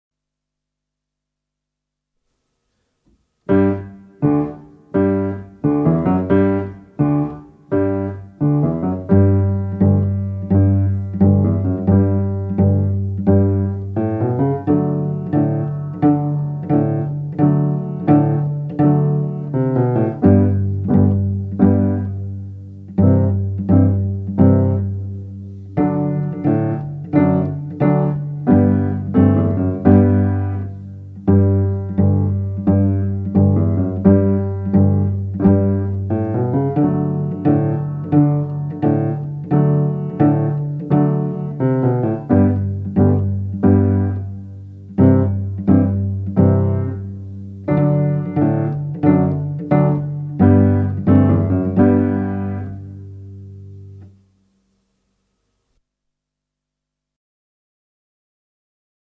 2 Under arrest! (Cello)